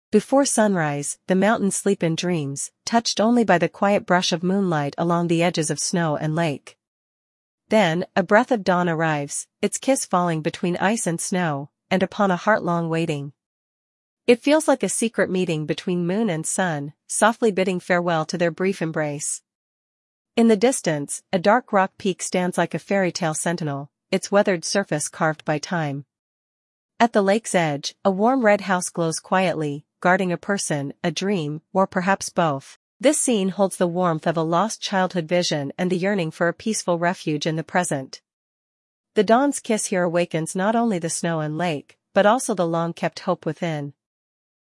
English audio guide